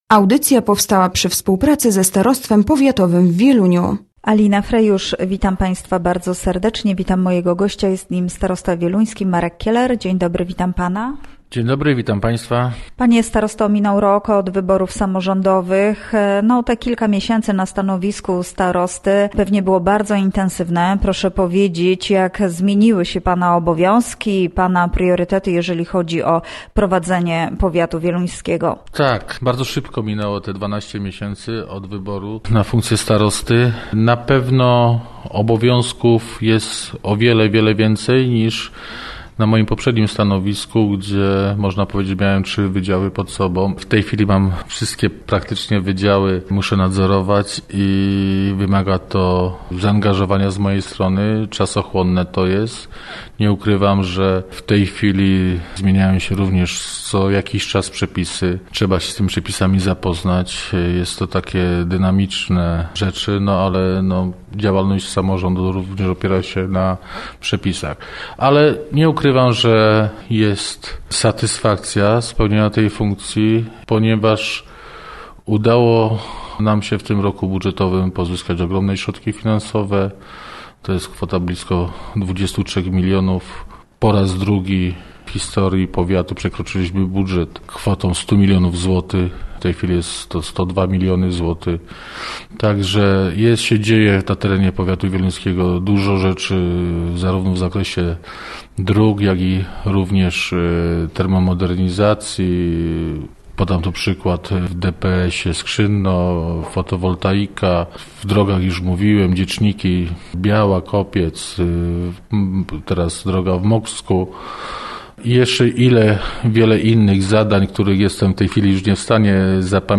Gościem Radia ZW był starosta wieluński, Marek Kieler